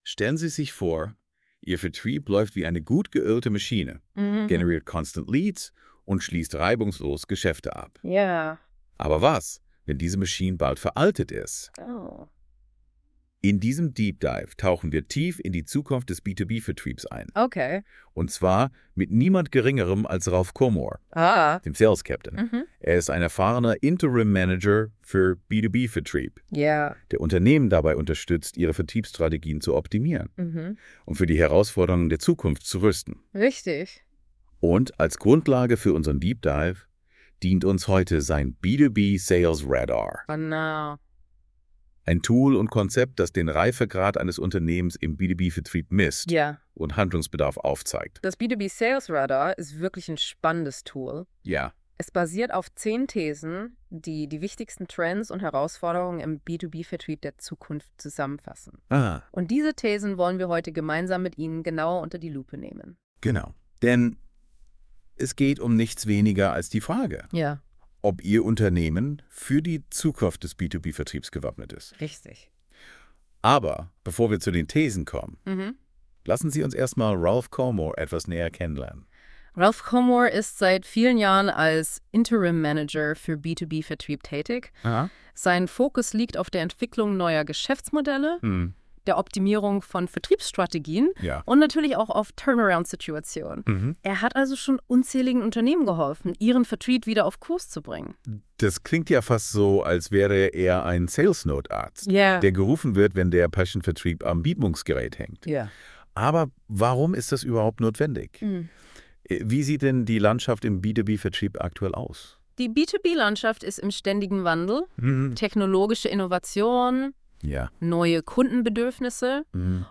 Der Podcast wurde von künstlicher Intelligenz generiert und mit z.T. fiktiven Situationen angereichert.
Entschuldigung für die eine oder andere Verwirrung (Zahlen werden nicht richtig gesprochen, wechselnde Stimmen, …) und das manchmal holprige Deutsch: Das Tool ist 2025-01 noch in der Beta-Phase…